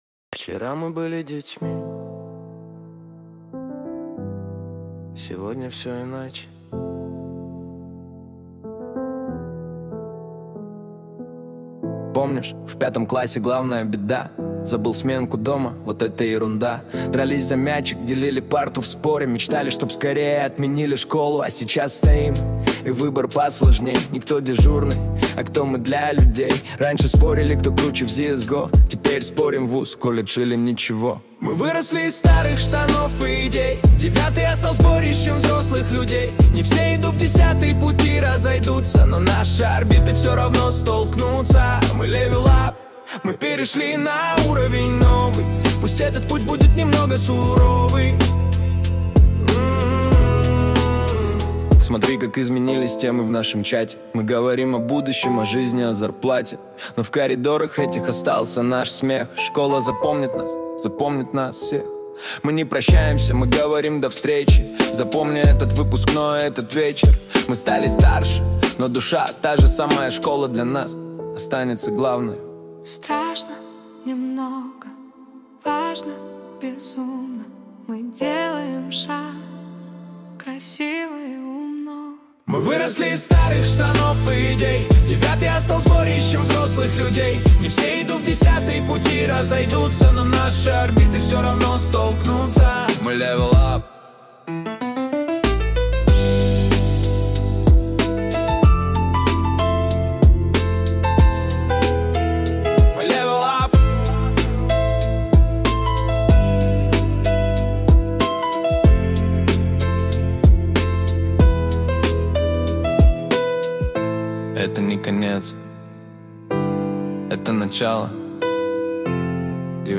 Жанр: Осознанный хип-хоп и лиричный Rap
🎹 Слушать пример (Piano Beat, 95 BPM):
🎵 Стиль: Conscious Hip-Hop / Storytelling Скачать MP3